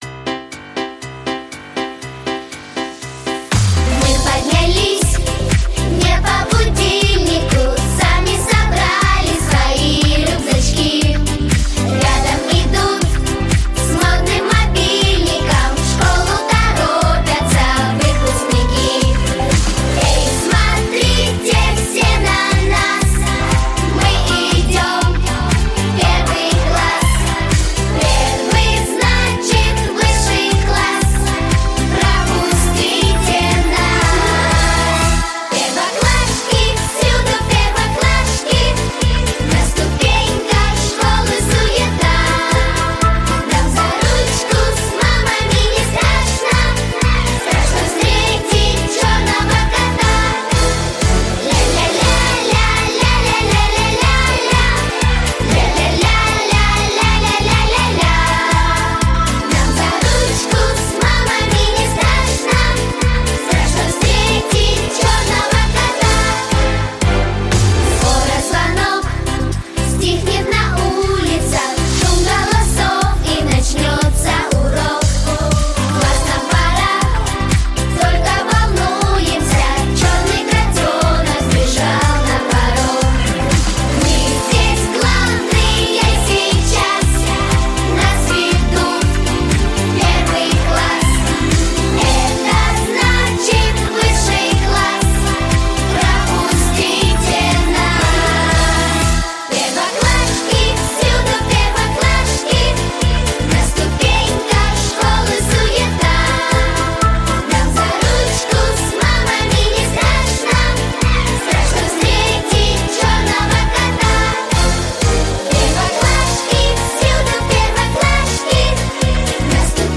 • Качество: Хорошее
• Жанр: Детские песни
Детская песня